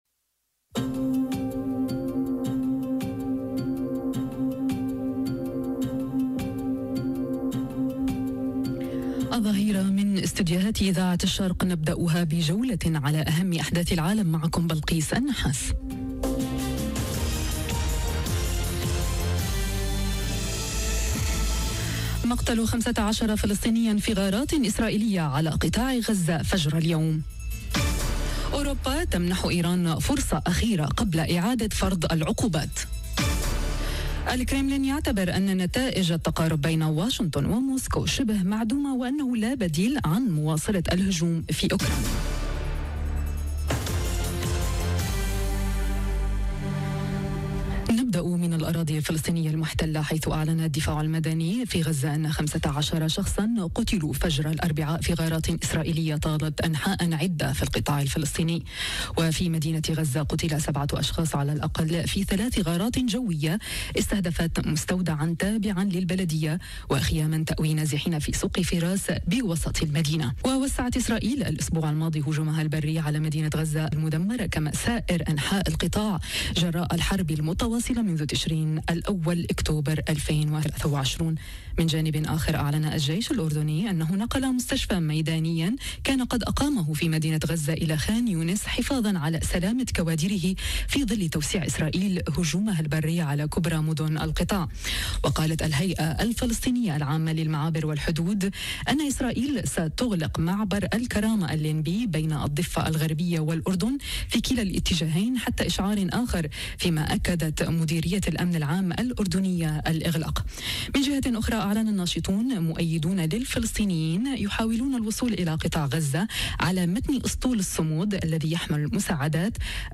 نشرة أخبار الظهيرة: أوروبا تمنح إيران "فرصة أخيرة" قبل إعادة فرض العقوبات، وقتلى في غزة - Radio ORIENT، إذاعة الشرق من باريس